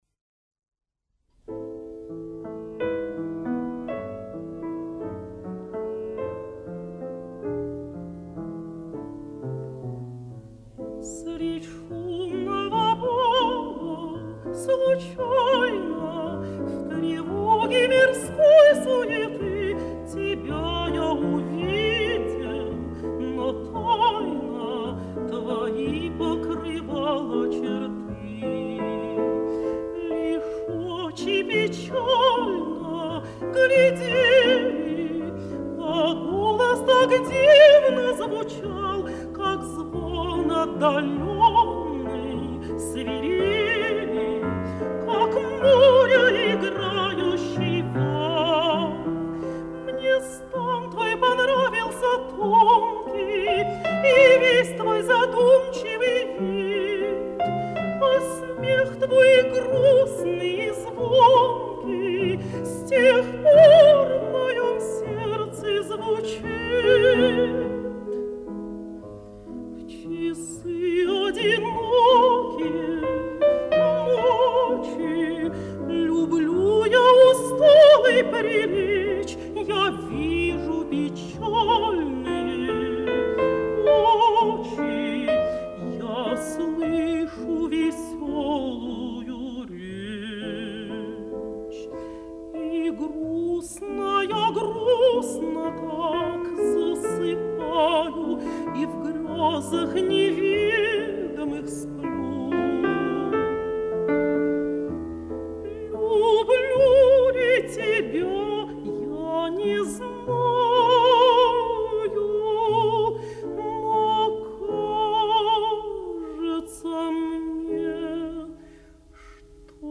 Партия фортепиано